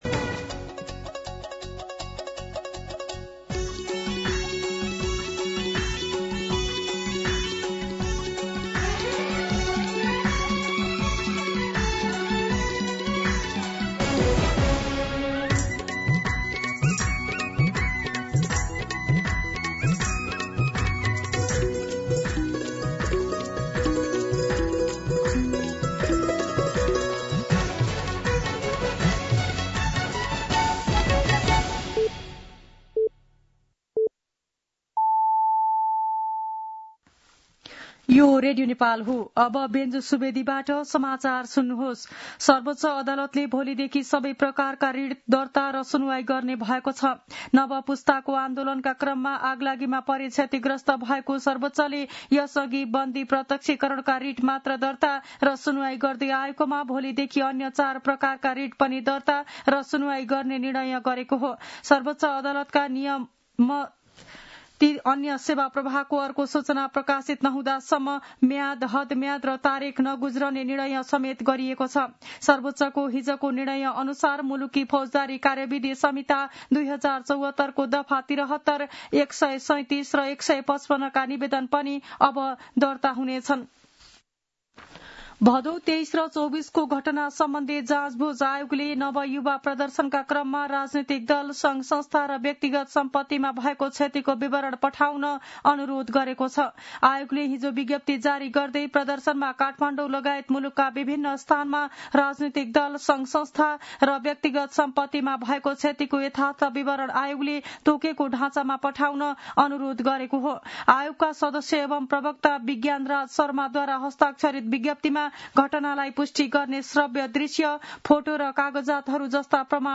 मध्यान्ह १२ बजेको नेपाली समाचार : १८ पुष , २०२६
12-pm-Nepali-News-4.mp3